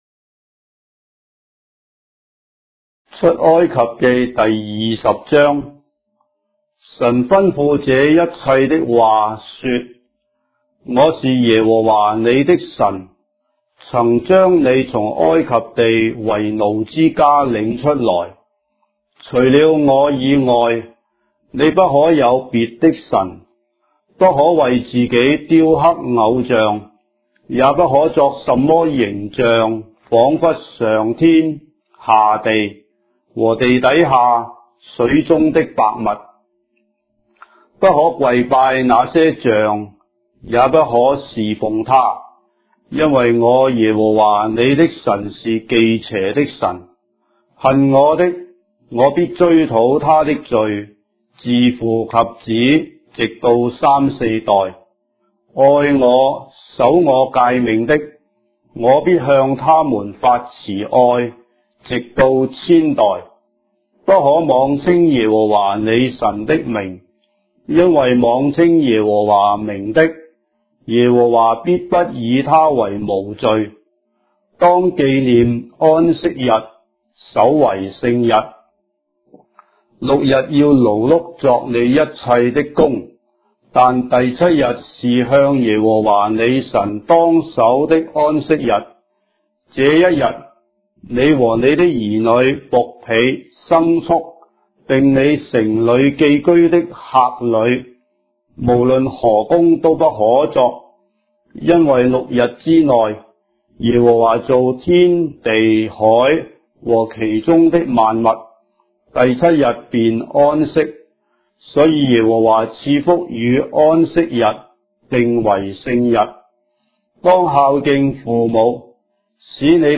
章的聖經在中國的語言，音頻旁白- Exodus, chapter 20 of the Holy Bible in Traditional Chinese